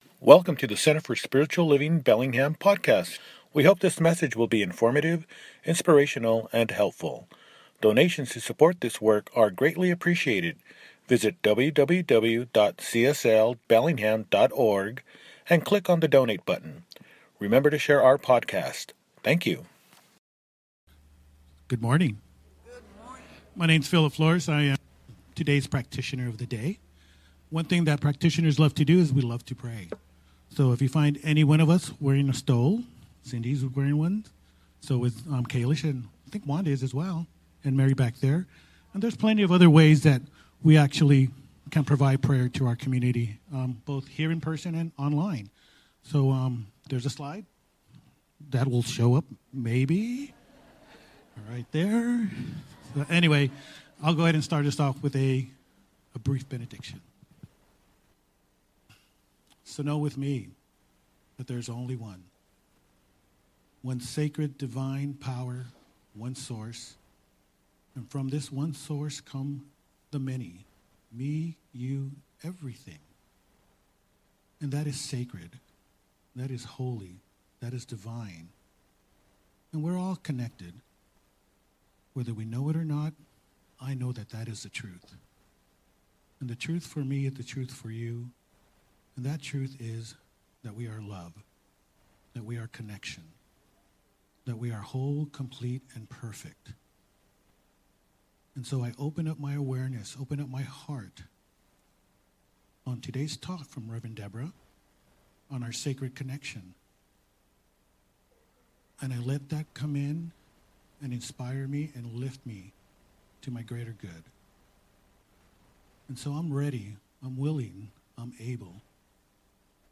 Our Sacred Connection – Celebration Service